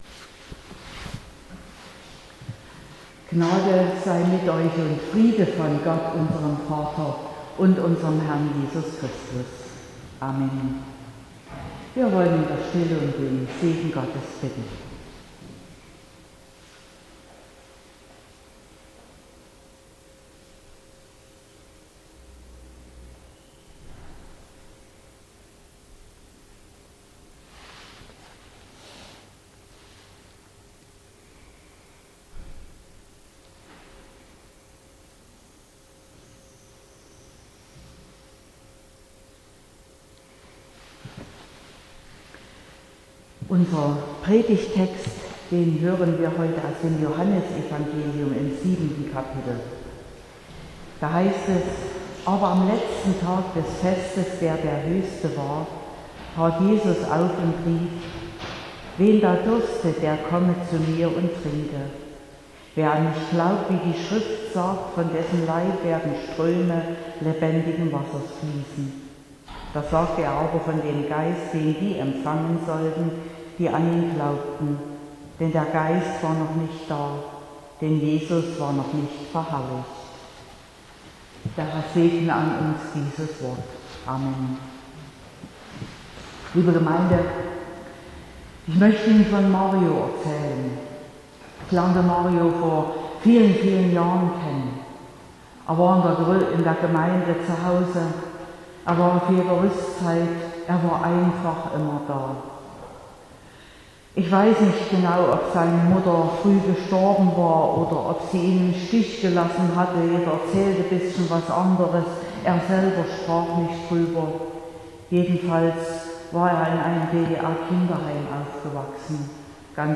16.05.2021 – Gottesdienst
Predigt (Audio): 2021-05-16_Da_muss_mehr_Leben_im_Leben_sein.mp3 (26,4 MB)